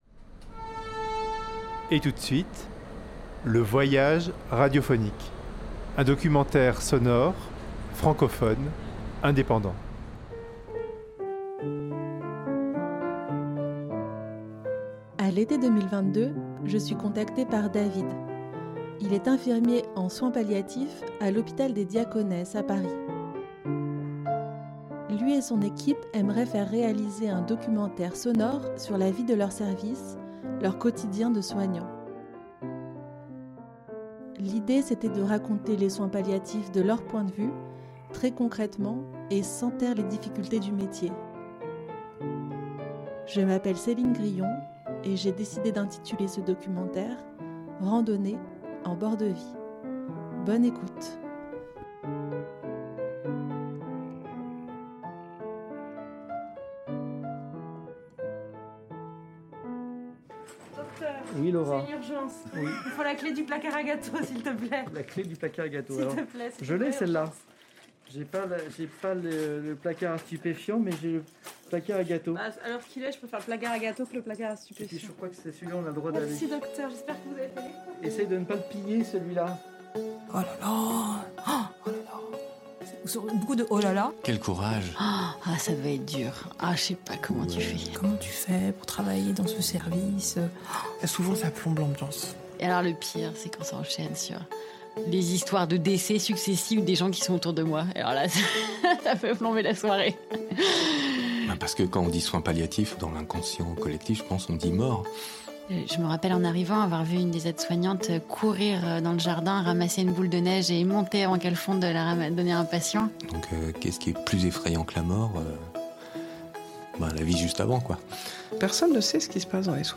l’unité de soins palliatifs du Groupe Hospitalier Diaconesses Croix Saint-Simon à Paris.
Loin des clichés, ce documentaire sonore donne la parole aux professionnelles de santé, aux patients et à leurs familles, pour faire le portrait sensible d’un service où on parle de tout, sans tabous : de vie et de mort, d’amour, de violence et d’émotions intenses, de désirs et de projets, de la douleur et des moyens de la soulager.